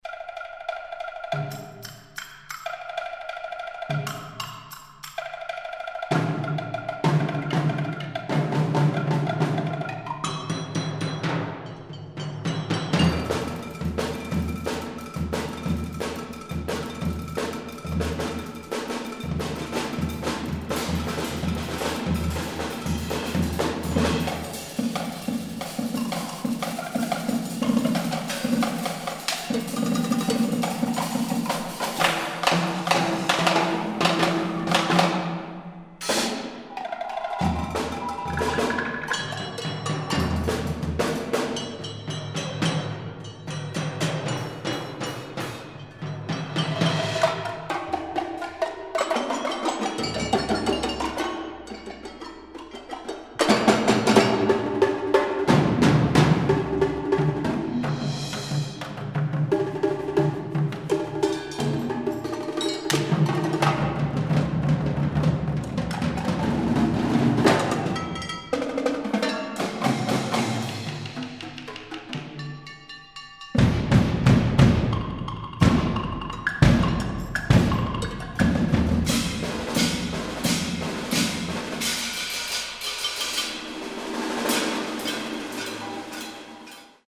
modern classical music for percussion
for eight percussionists